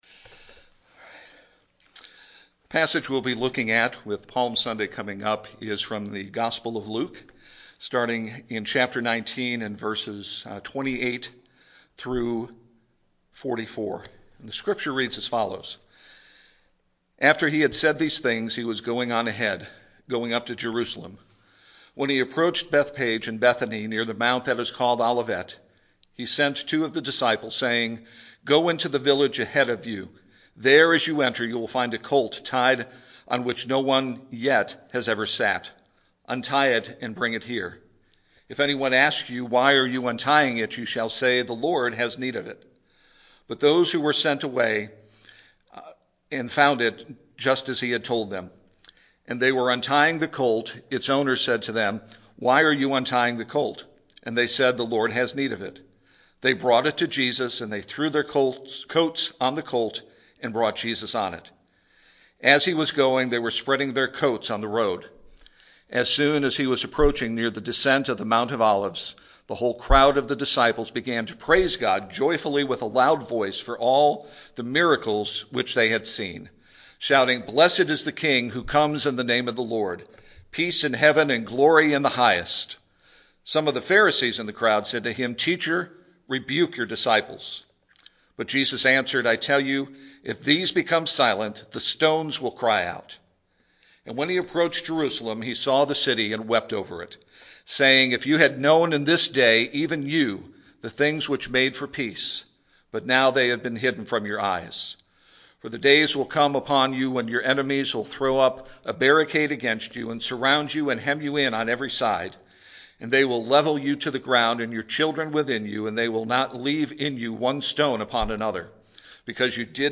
Music: Here I Am to Worship; Sermon: What Makes Jesus Weep?